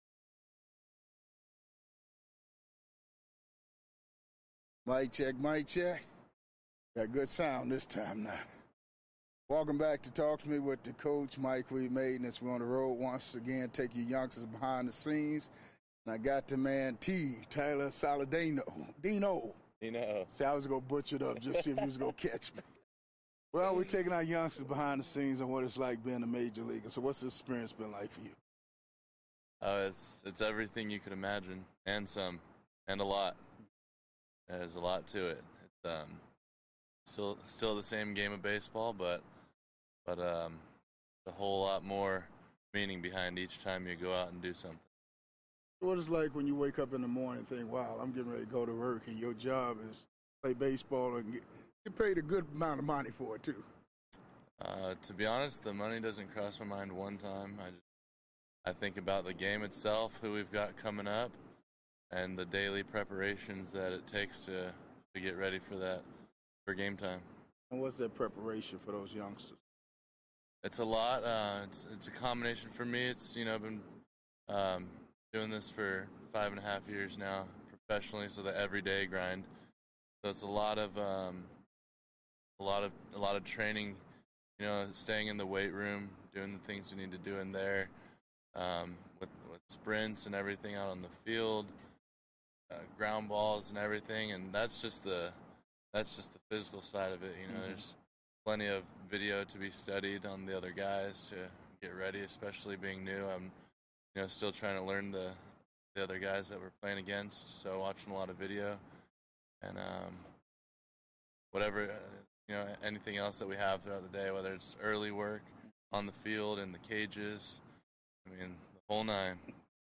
Taking you behind the scenes full uncut and unedited MLB interviews with past and present players